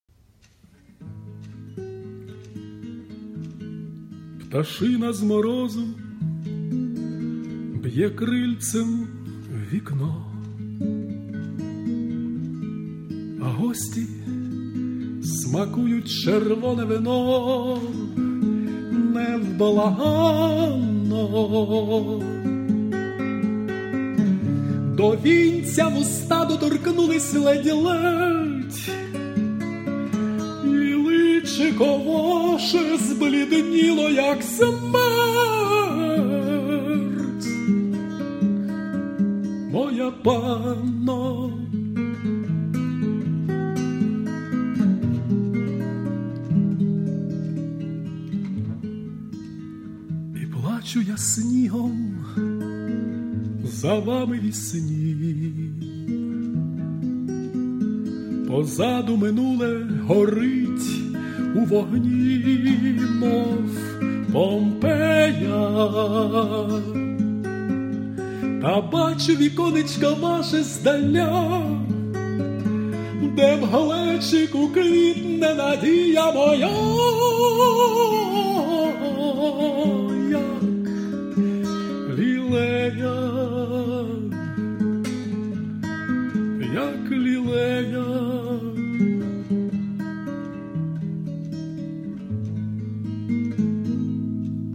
Авторська пісня